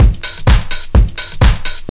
B3HOUSE124.wav